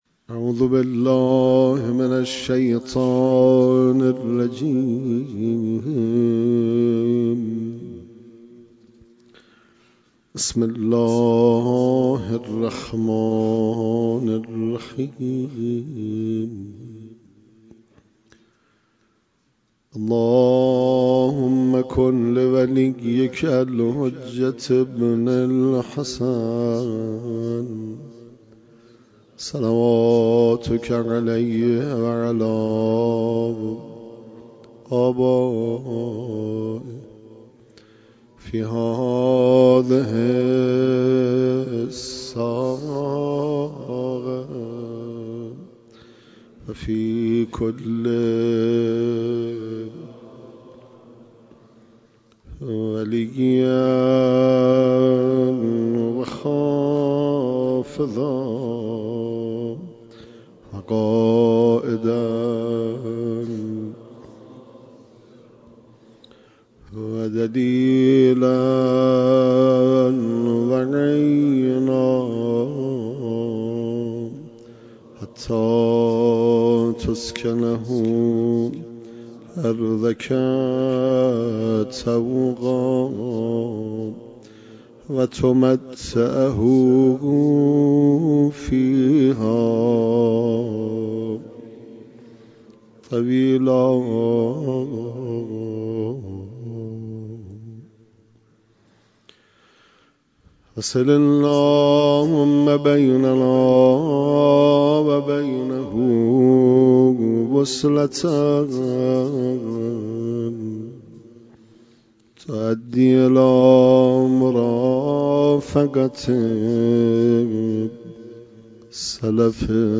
حسینیه حق شناس
زیارت آل یاسین
روز عاشورا 1402